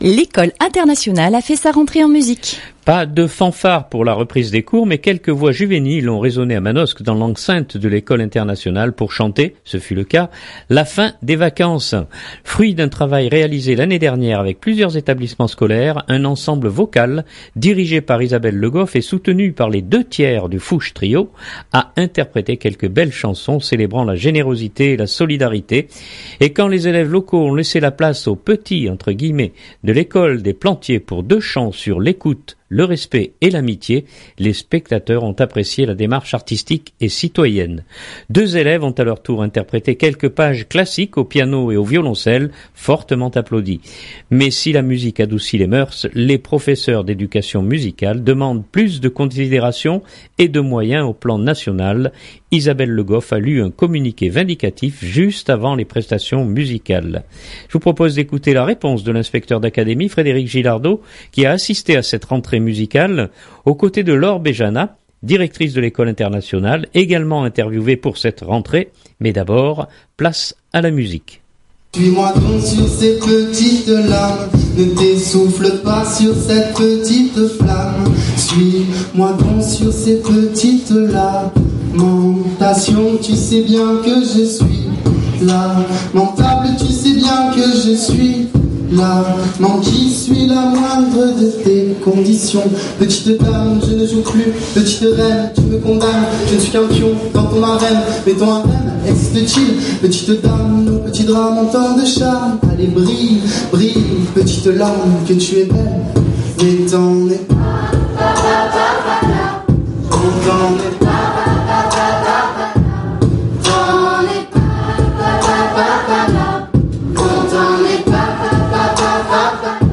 (2.19 Mo) Pas de fanfare pour la reprise des cours mais quelques voix juvéniles ont résonné dans l’enceinte de l’Ecole Internationale pour chanter – ce fut le cas – la fin des vacances.
Et quand les élèves locaux ont laissé la place aux « petits » de l’école des Plantiers pour deux chants sur l’écoute, le respect et l’amitié, les spectateurs ont apprécié la démarche artistique et citoyenne. Deux élèves ont à leur tour interprété quelques pages classiques au piano et au violoncelle fortement applaudies.
Mais d’abord, place à la musique…